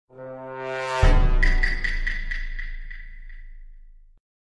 Among-Us-Role-Reveal-Sound-Sound-Effects-Download-.mp3